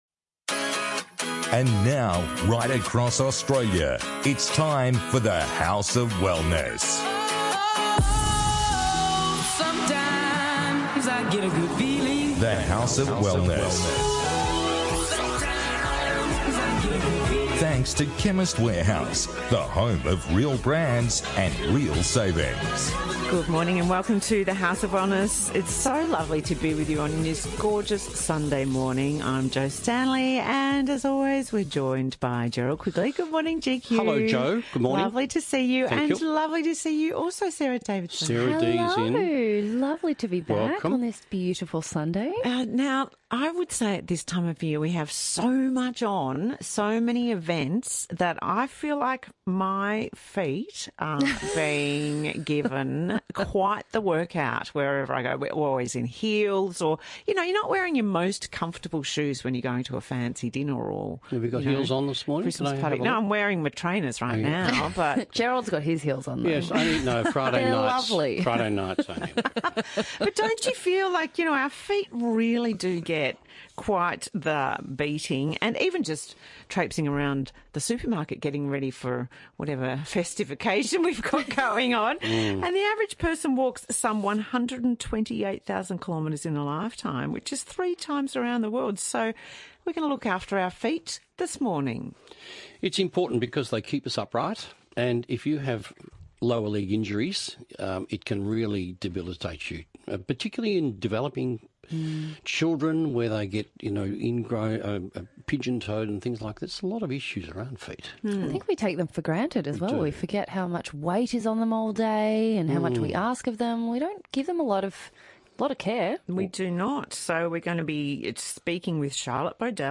The House of Wellness Radio - Full Show 4th December 2022